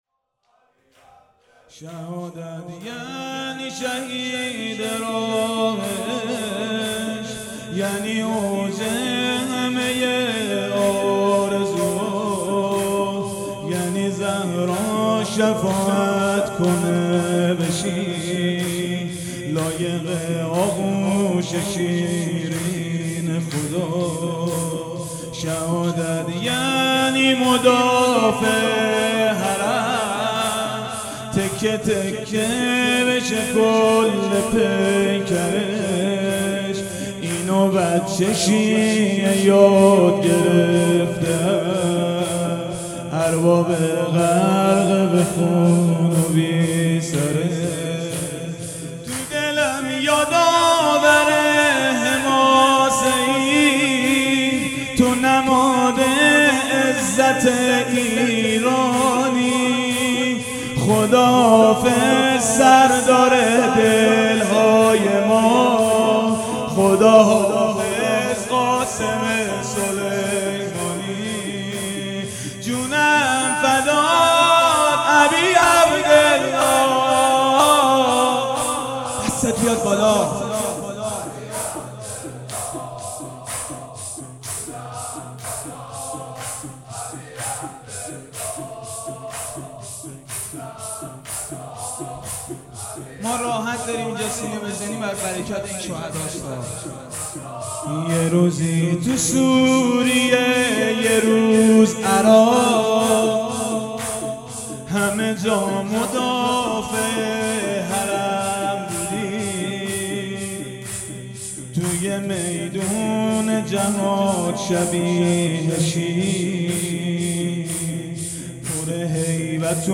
صوت مداحی
در مراسم گرامیداشت شهادت سردار حاج قاسم سلیمانی در هیئت سپهسالار کربلا به مداحی درباره شهید قاسم سلیمانی پرداخت.